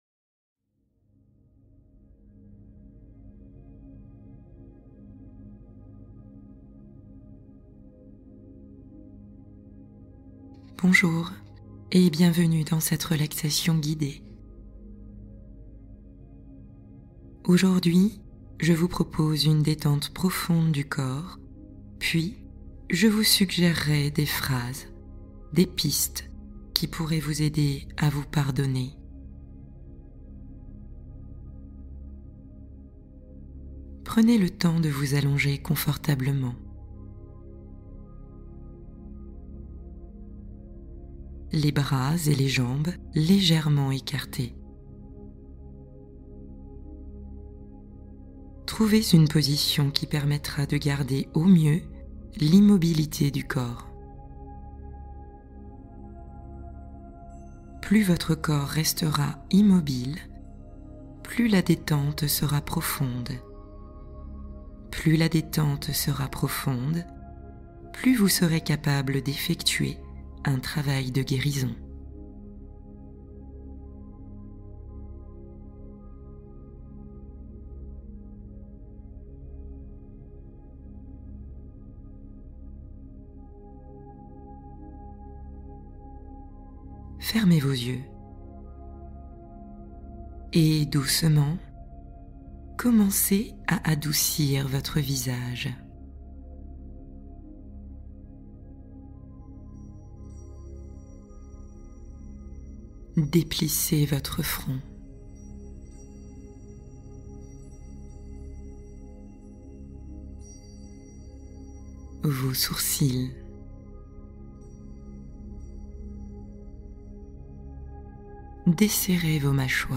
Transition vers la nuit : relaxation guidée pour apaiser l’esprit